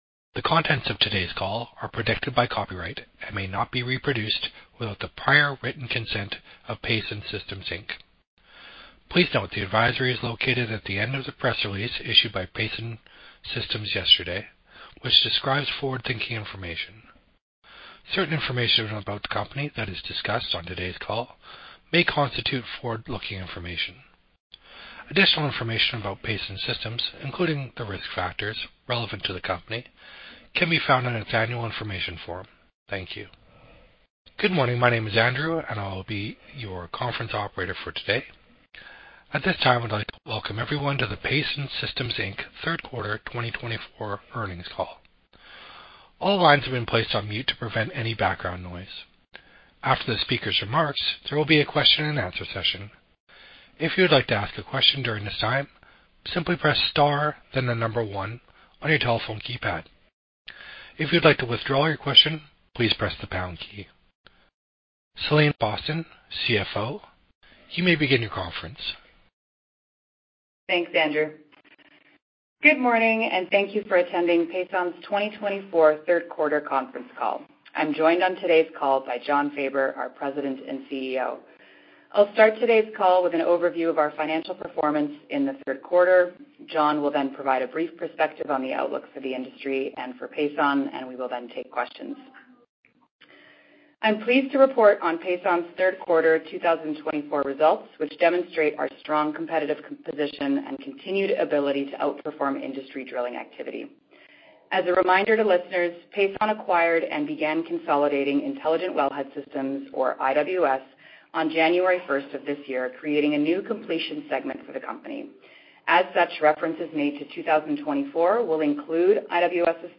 Pason_Conference_Call_Q3_2024.mp3